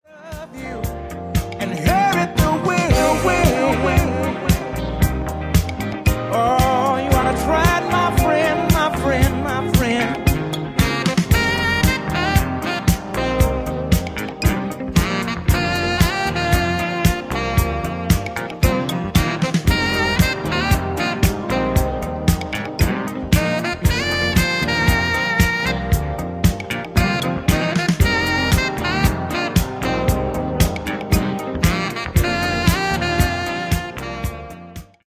Genere:   Jazz Funk